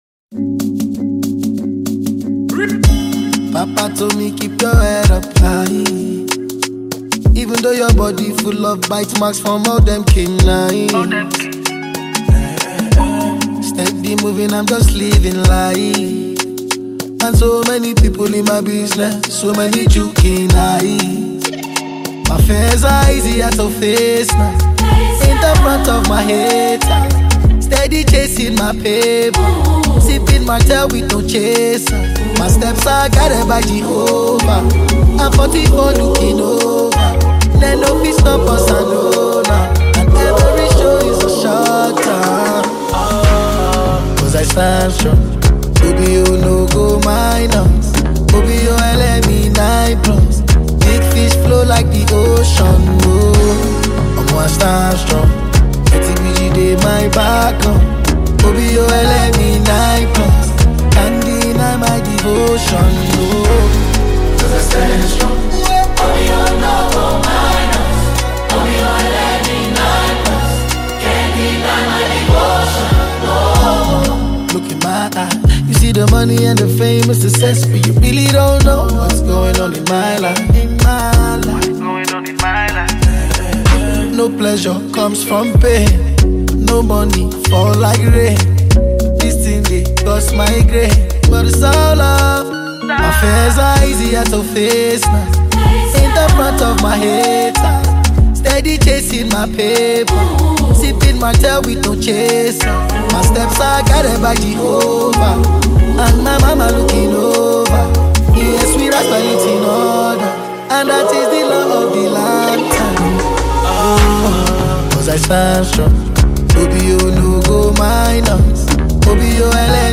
United States Choir